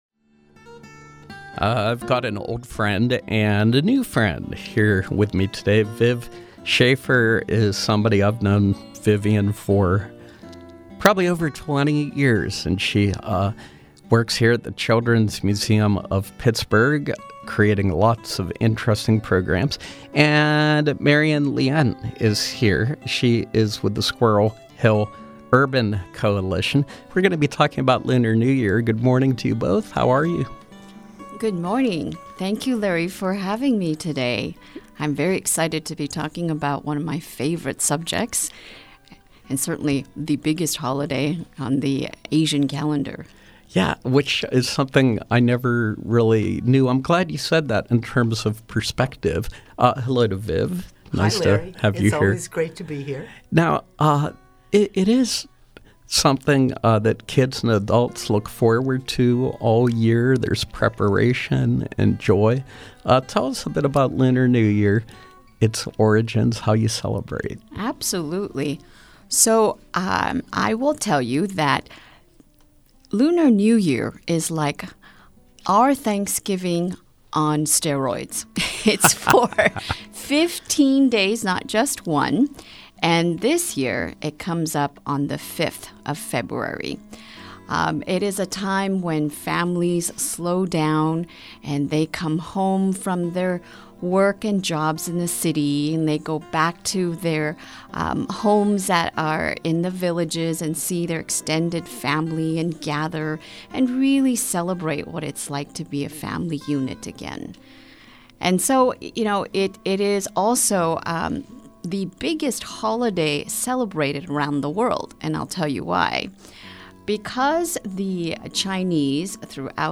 In Studio Pop-Up: Lunar New Year Celebration